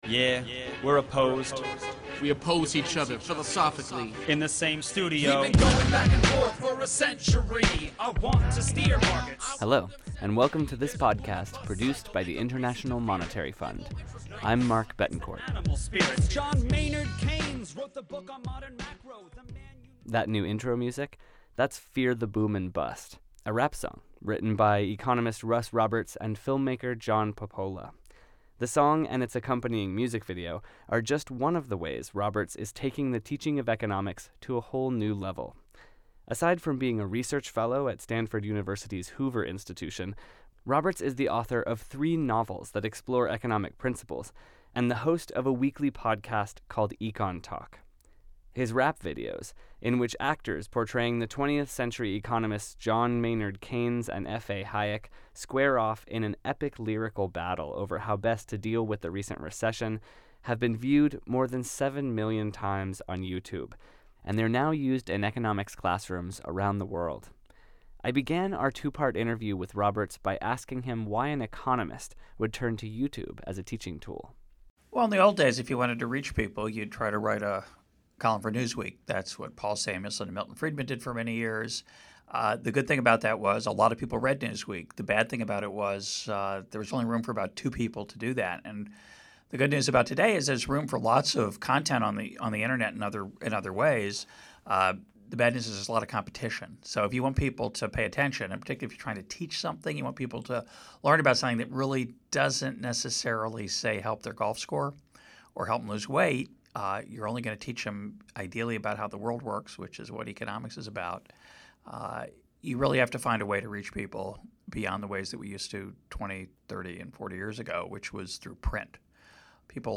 Russ Roberts, research fellow, Stanford University, Hoover Institution, and author